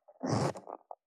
424,ジッパー,チャックの音,洋服関係音,ジー,バリバリ,カチャ,ガチャ,シュッ,パチン,
ジッパー